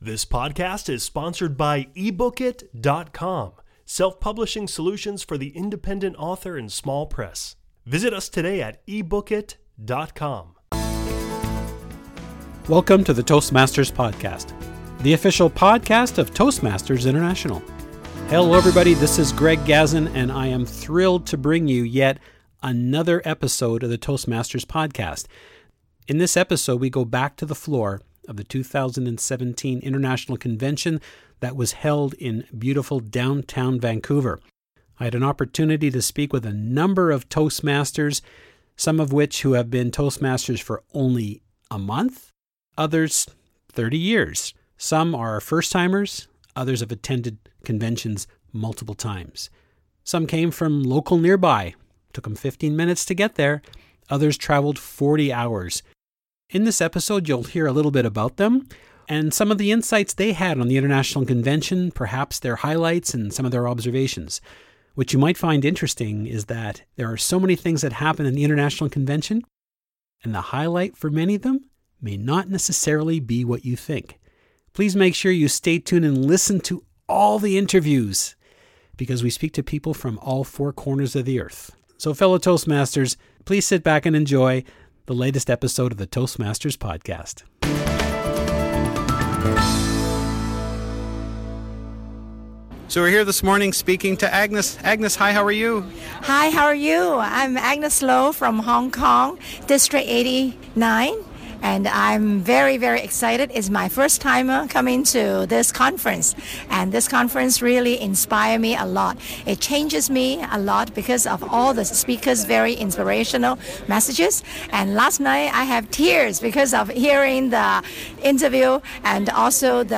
You'll hear from Toastmasters from all around the globe, ranging from local Toastmasters to others who trekked up to 40 hours to get to Vancouver to attend the premier event.